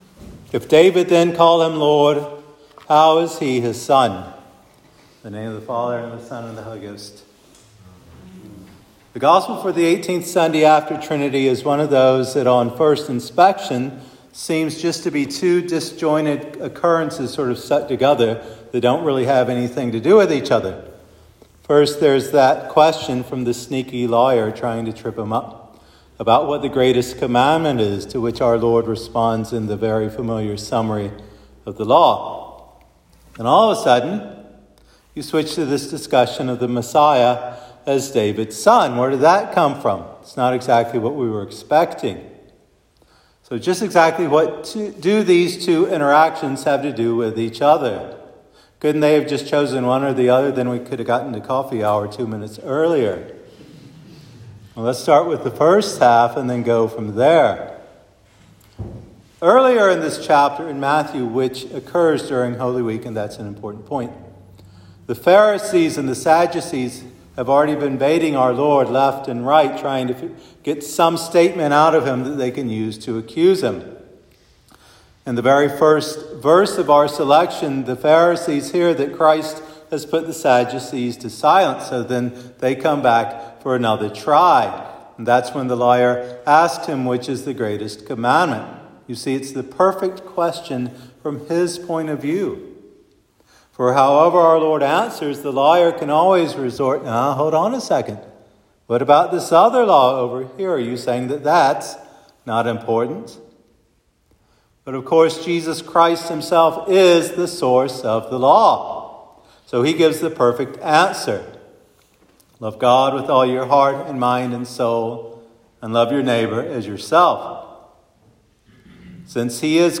Sermon for Trinity 18